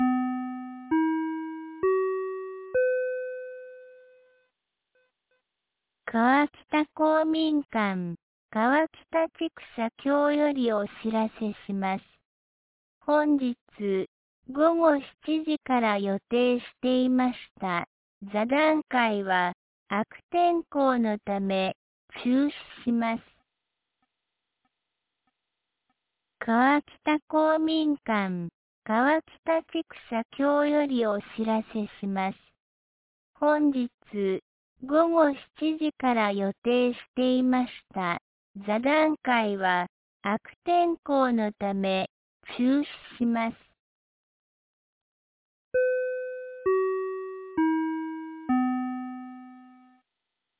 2025年07月17日 17時10分に、安芸市より川北へ放送がありました。